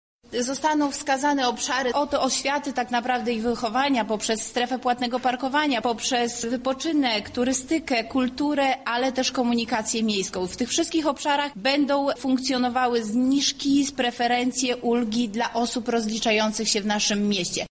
Jak ma wyglądać działanie takiej karty tłumaczy Beata Stepaniuk-Kuśmierzak, zastępca prezydenta miasta: